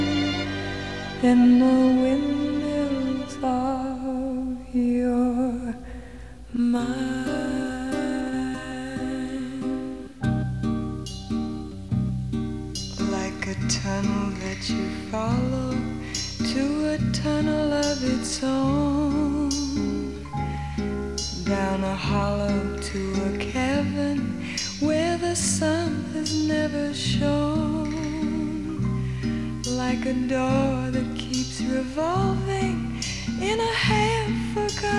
Жанр: Поп / R&b / Рок / Соул